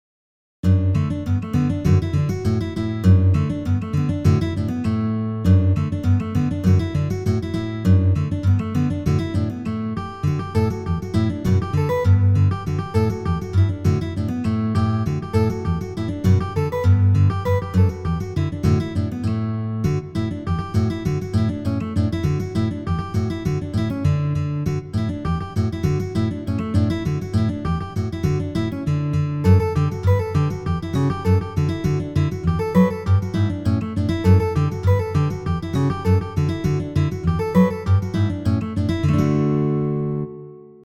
Arrangements for solo guitar of the